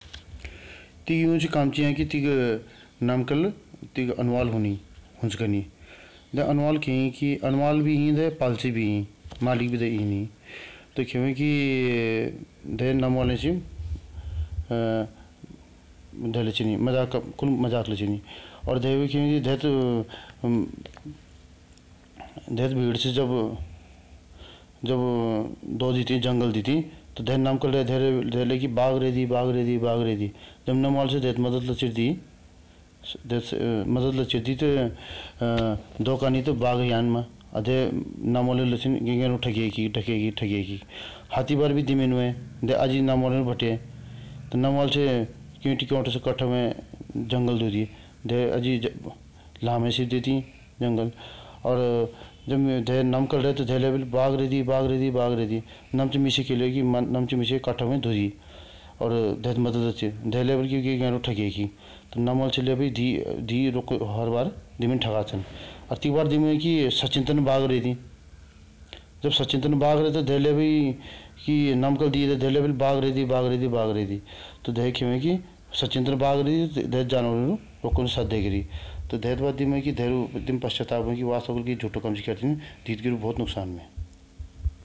Retelling of a story in Rongpo